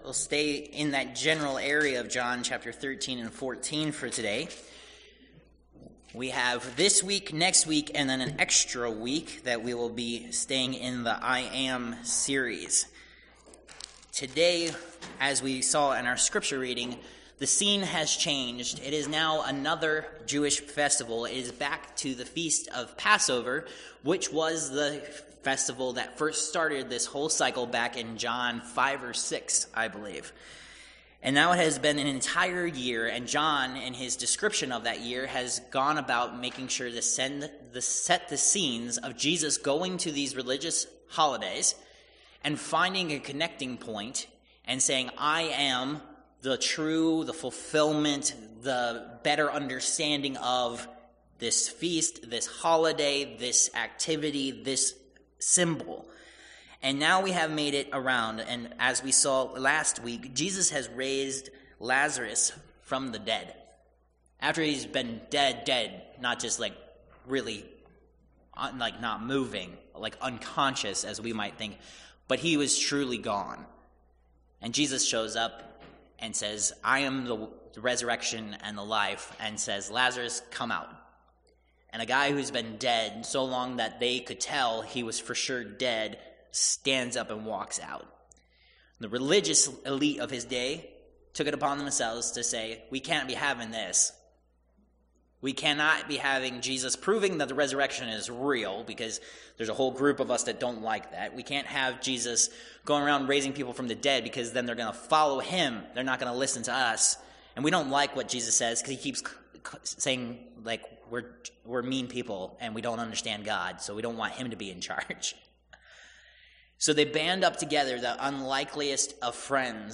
Passage: John 14:1-26 Service Type: Worship Service « Protected